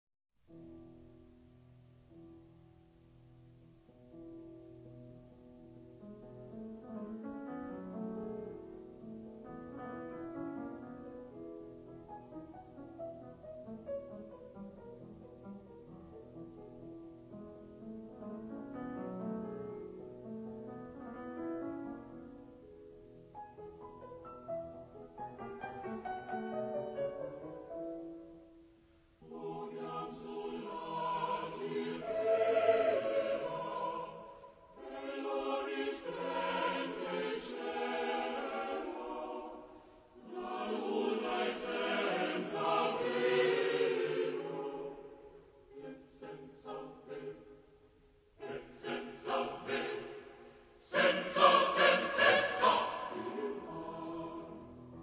Genre-Style-Form: Secular ; Romantic
Type of Choir: SATB  (4 mixed voices )
Instruments: Violin (2) ; Piano (1)
Tonality: E major